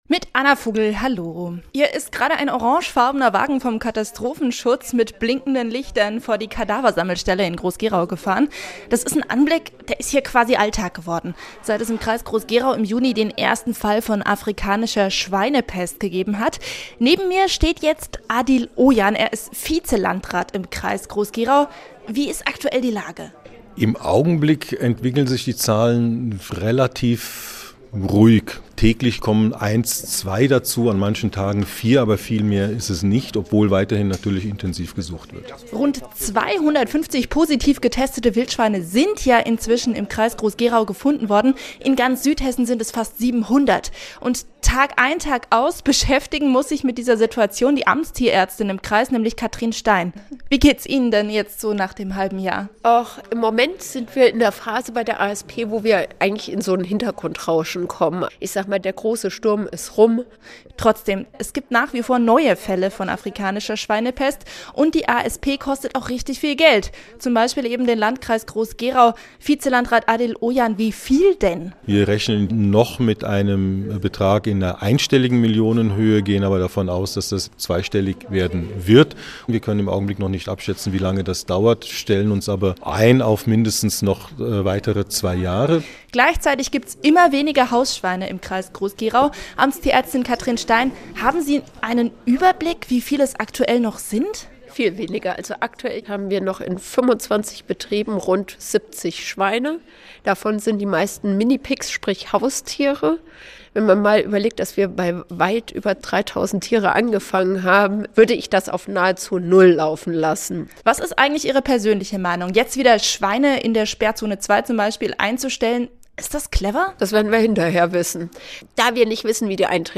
Mittags eine aktuelle Reportage des Studios Darmstadt für die Region